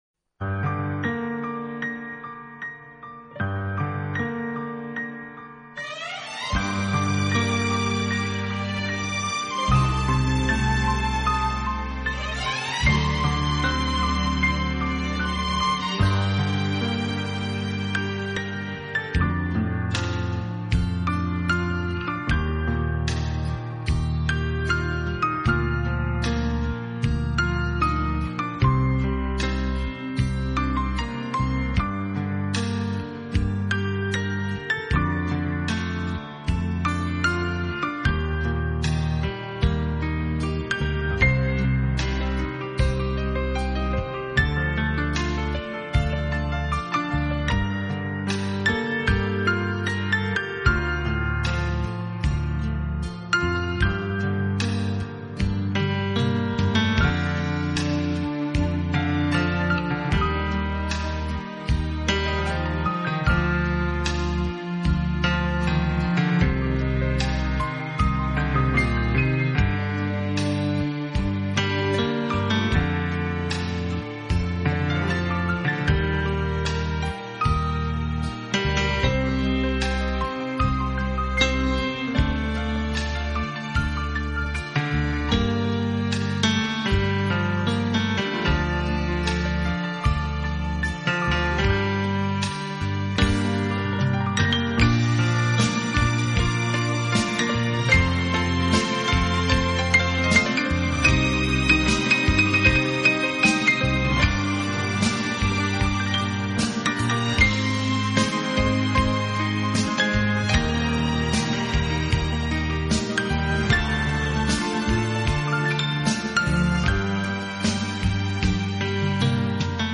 这是一套非常经典的老曲目经过改编用钢琴重新演绎的系列专辑。
本套CD全部钢琴演奏，